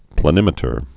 (plə-nĭmĭ-tər, plā-)